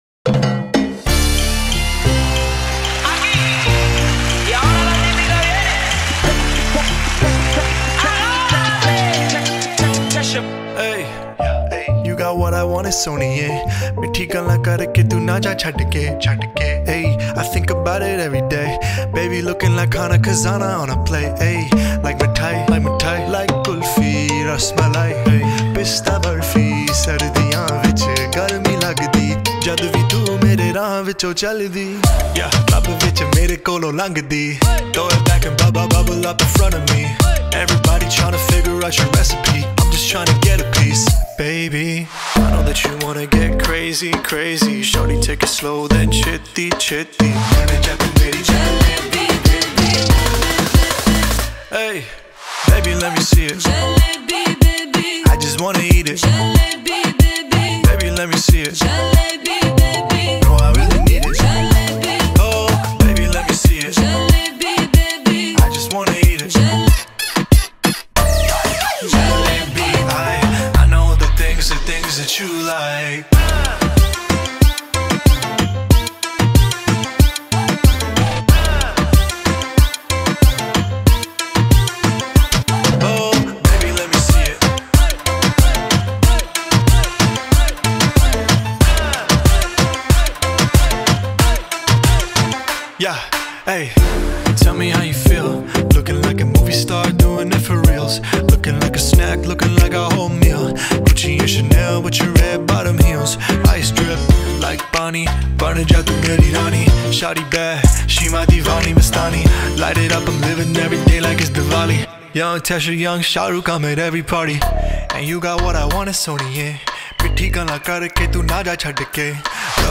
فضای آهنگ شاد، رقص‌محور و کاملاً مناسب ویدئوهای پرانرژی است.
شاد